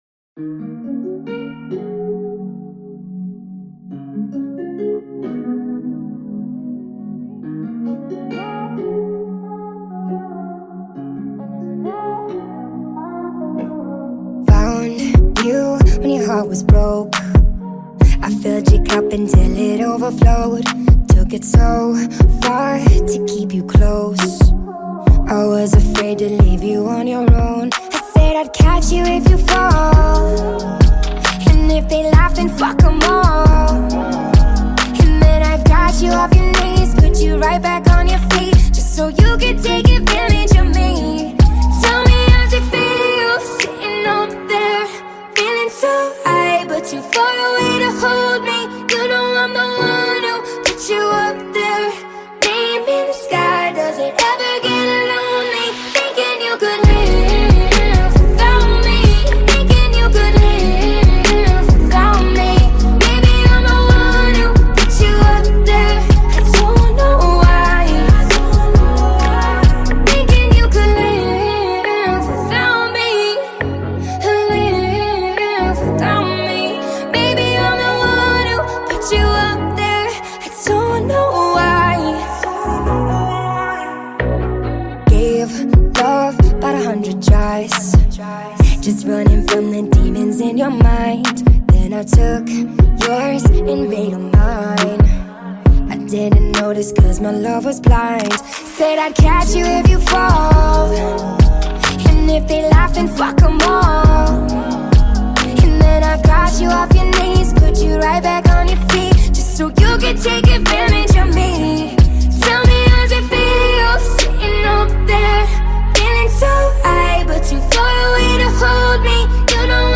sad emotional throwback music